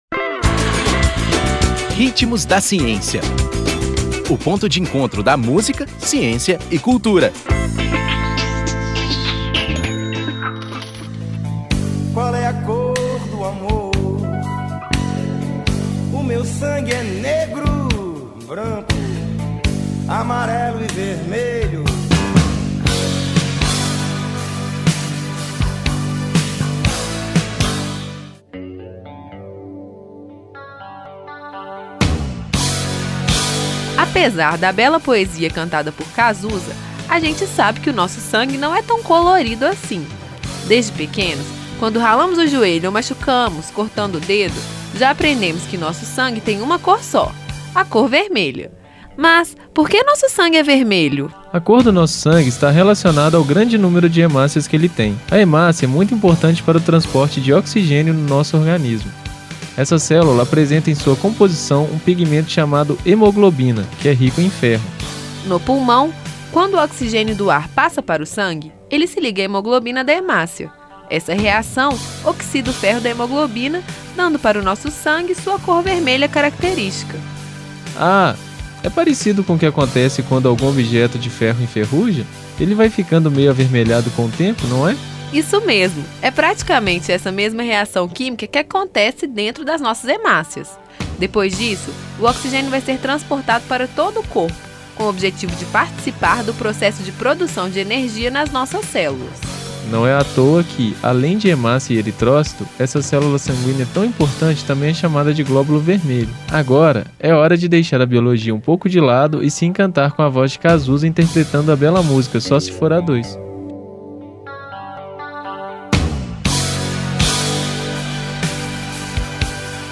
Nome da música: Só se for a dois
Intérprete: Cazuza